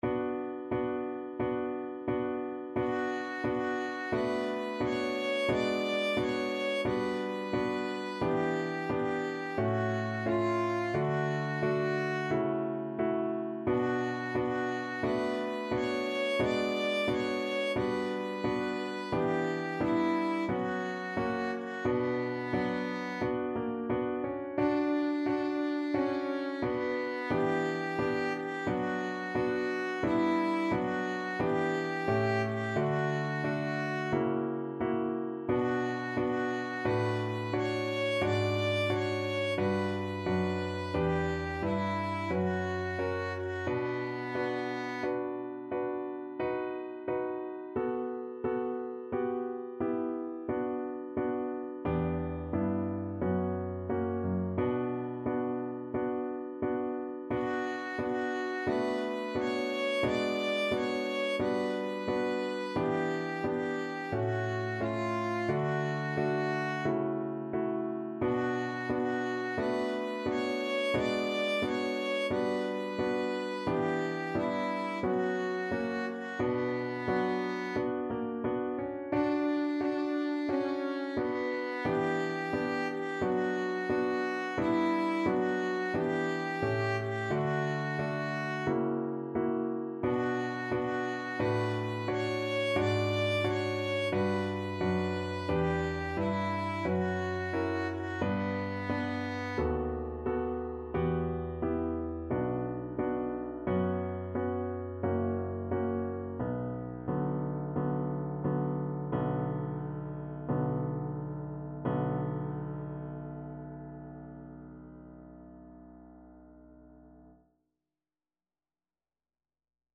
Violin
D major (Sounding Pitch) (View more D major Music for Violin )
Andante =c.88
4/4 (View more 4/4 Music)
Classical (View more Classical Violin Music)
Japanese
kojo_no_tsuki_VLN.mp3